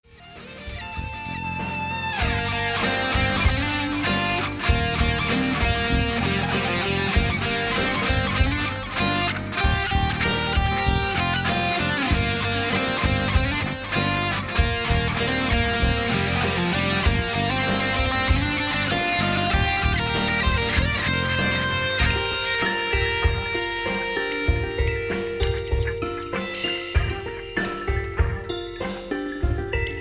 Electric Guitar instrumental single track. Home recording.
All guitars, keyboards, bass and drums